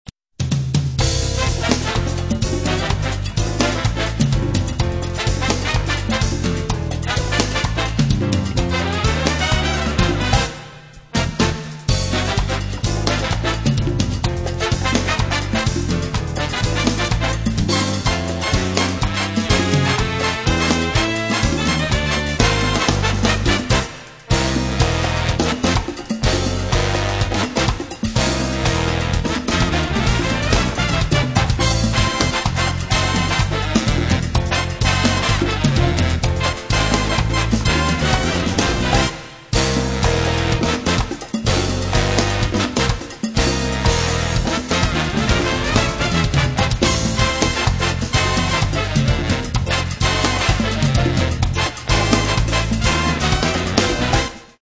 Piano
Trompettes
Saxophones
Basse
Batterie
Trombones
Congas, TImbales Bongos, Percussion
Chekere, Tambora, Guijo, Percussion